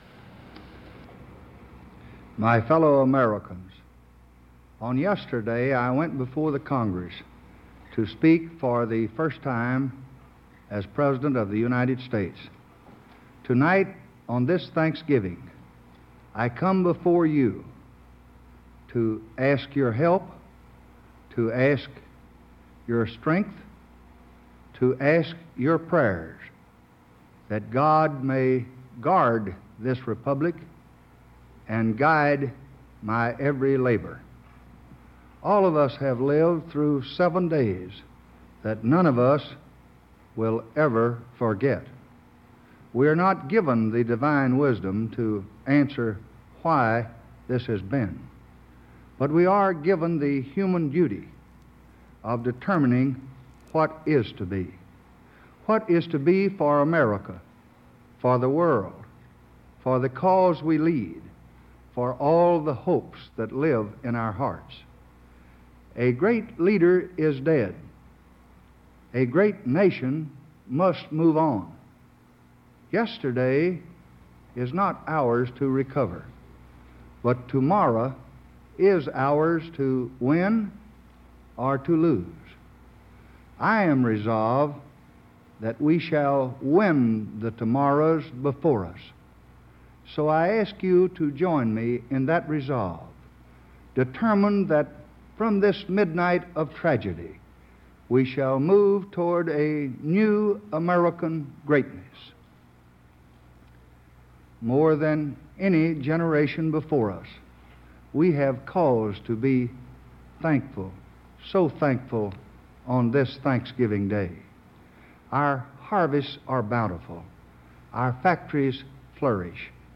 On November 28th 1963, newly sworn in president Lyndon B. Johnson, delivered a speech honoring president John F. Kennedy, who had died just a week earlier. His message is one of thanks and hope, as he details the coming together of the American people and the people of the world, in the face of such a tragedy.
This audio came to the AAPB as part of a WGBH radio broadcast that was recorded onto ¼ inch audio tape.
lbj_thanksgiving_speech.m4a